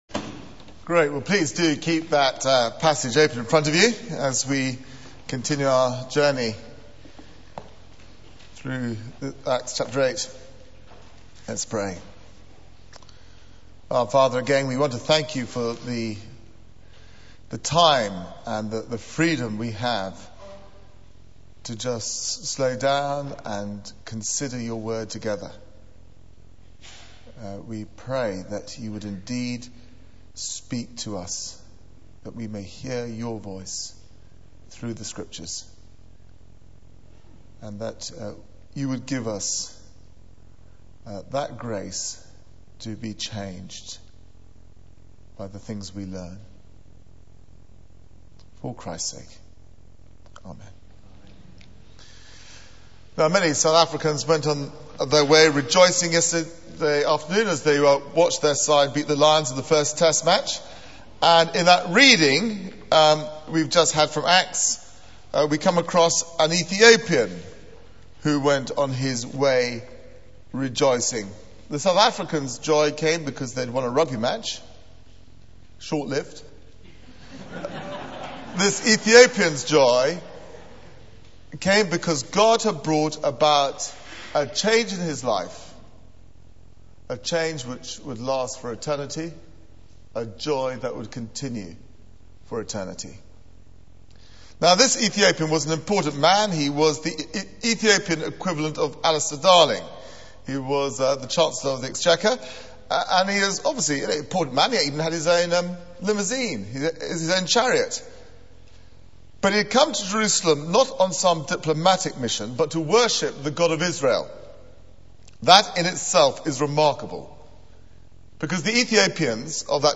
Media for 9:15am Service on Sun 21st Jun 2009 09:15 Speaker: Passage: Acts 8: 26 - 40 Series: Foundations for World Mission Theme: The Ethopian and the Gospel Sermon Search the media library There are recordings here going back several years.